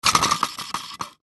Descarga de Sonidos mp3 Gratis: juguete 7.
juguetes-juguete-14-.mp3